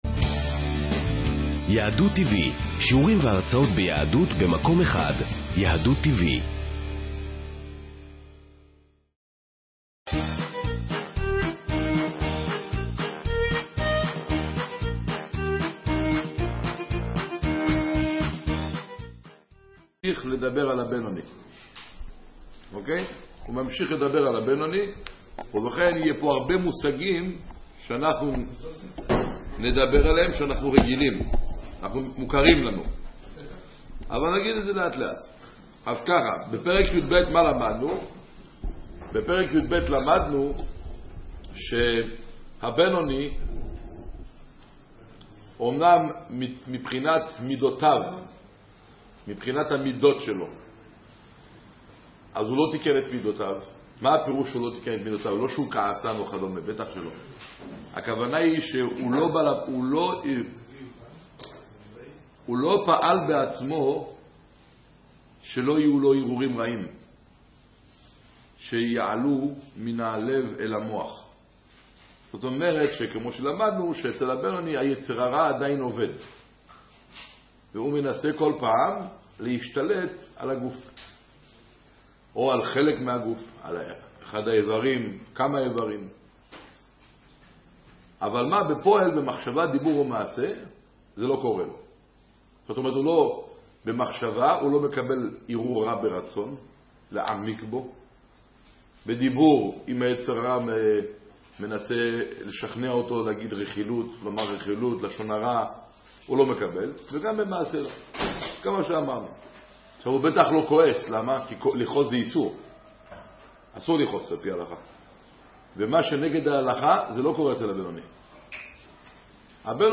לאיזה שופט צריך לציית? ● שיעור תניא